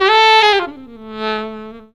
LOW SCALE.wav